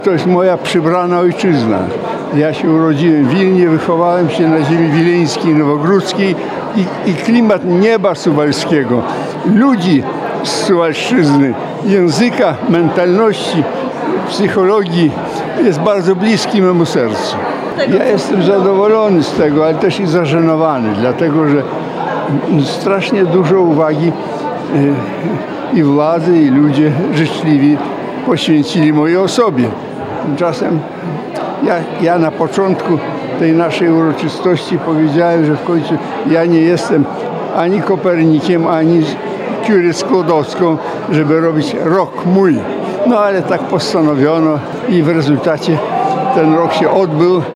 Benefis Andrzeja Strumiłły odbył się w sobotę (21.10) w Suwalskim Ośrodku Kultury.